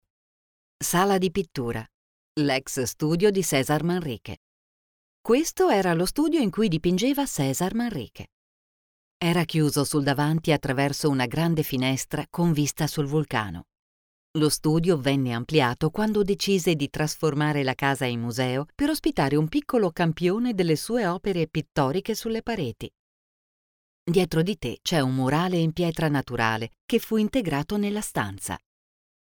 Female
Yng Adult (18-29), Adult (30-50)
Tour Guide
Museum Audio Guide
1002tour_guide.mp3